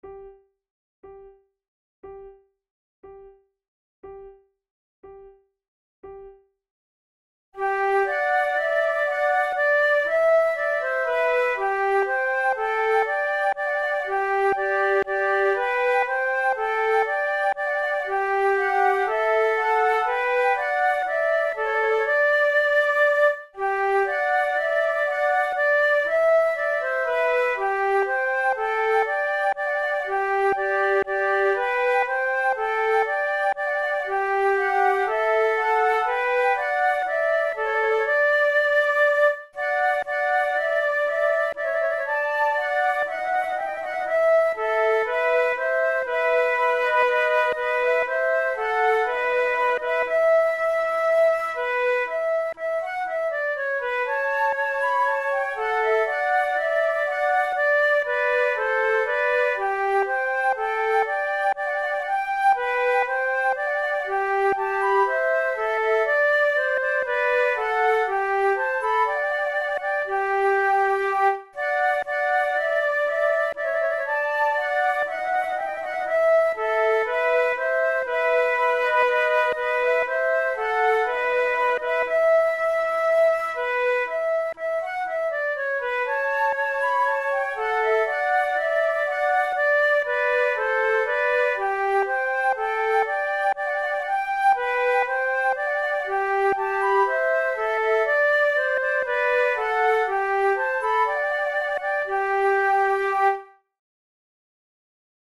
KeyG major
Tempo60 BPM
Play-along accompanimentMIDI (change tempo/key)
Baroque, Bourrées, Sonatas, Written for Flute